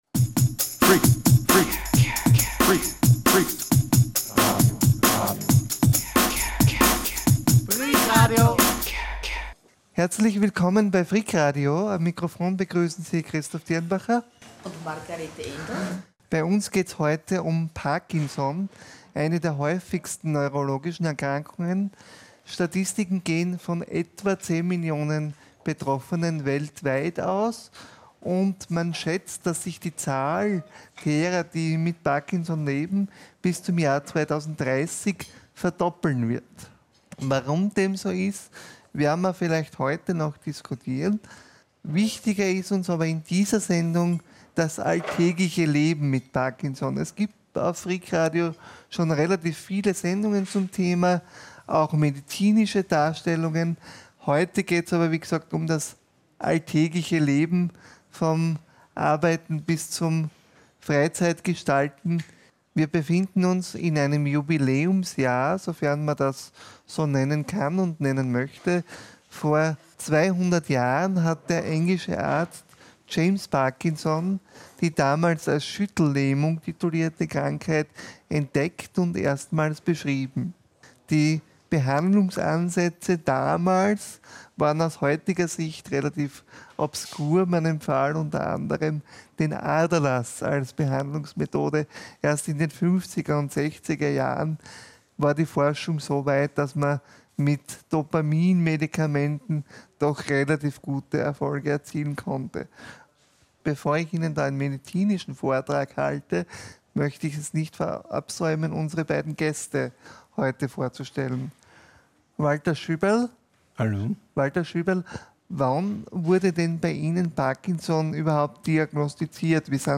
Freak Online - die Webplattform von Freak Radio, dem Radio über den barrierefreien Lifestyle.